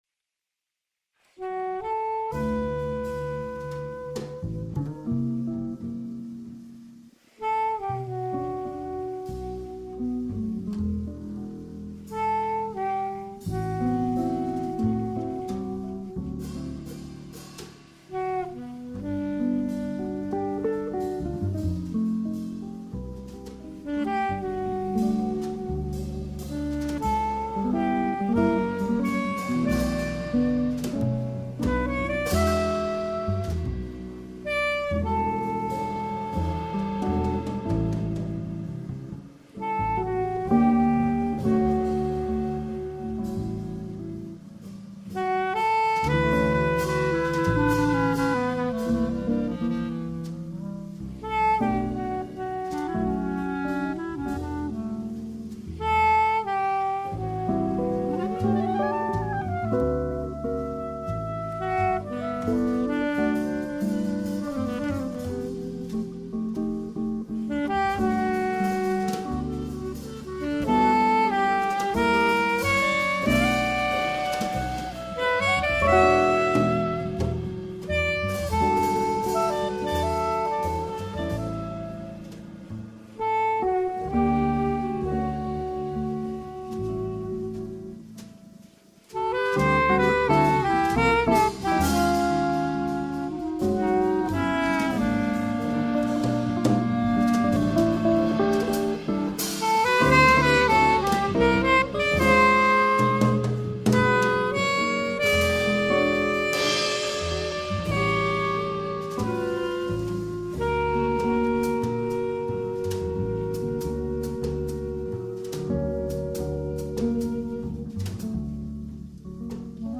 A Jazz Ensemble recording, recorded in a studio tuckers away in the hills above Marin County, CA.
Jazz Ensemble
jazzEnsemble.mp3